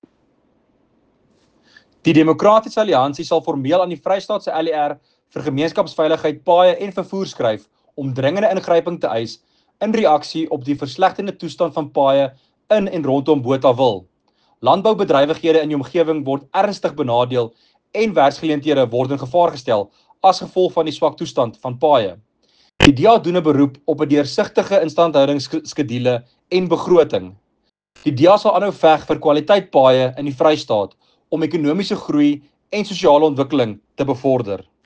Afrikaans soundbite by Werner Pretorius MPL with images here and here